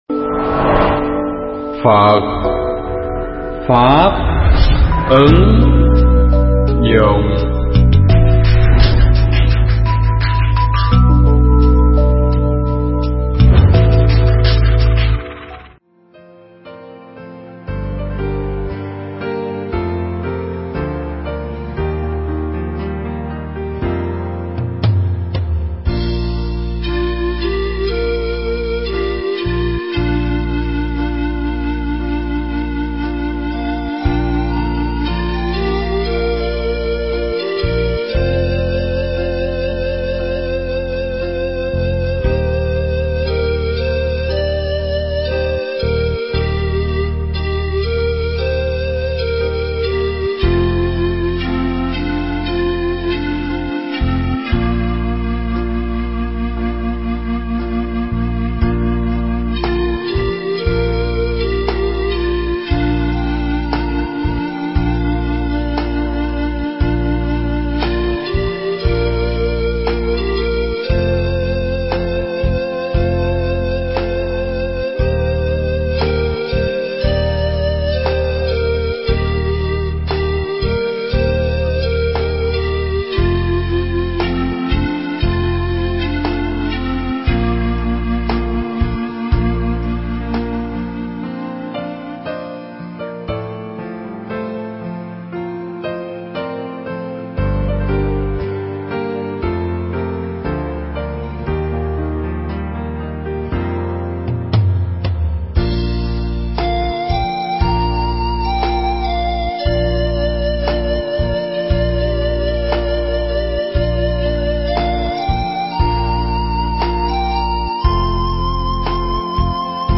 Mp3 Thuyết Giảng Để Trở Thành Phật Tử